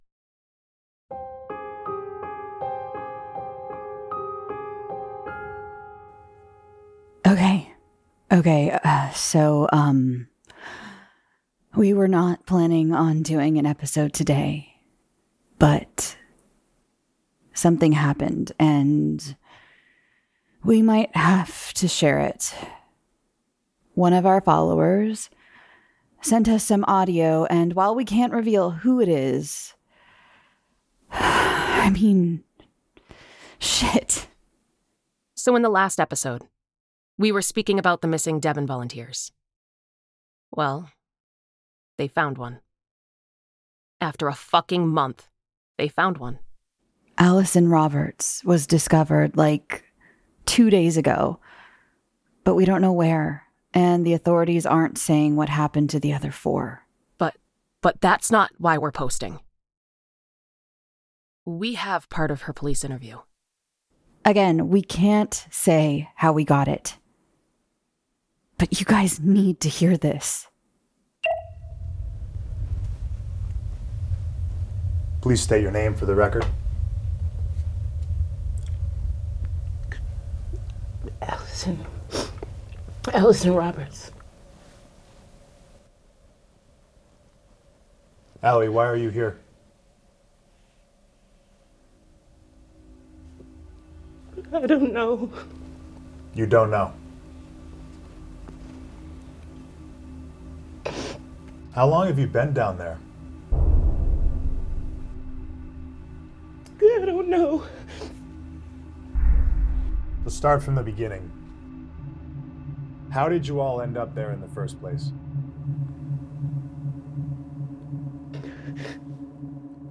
Episode 15 – POLICE INTERVIEW
With more questions than answers, the case continues to evolve as one of the volunteers is discovered. A leaked interview is the only thing the public has to go on, as authorities continue to withhold the truth.